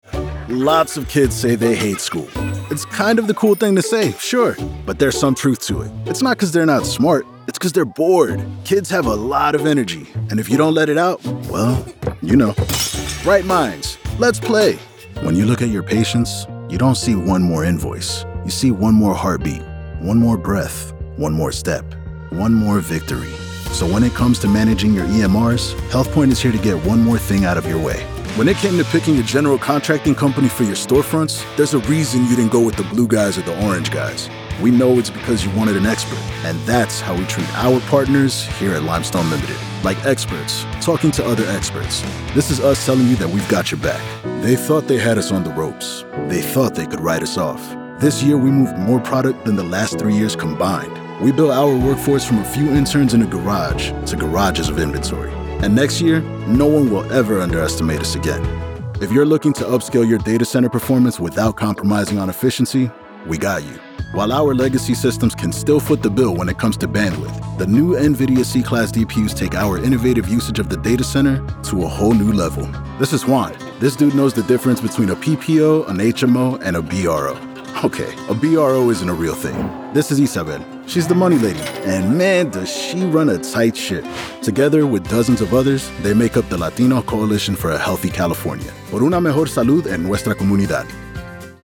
Corporate
• Conditioned room with an assortment of bass traps
• Noise floor of -60dB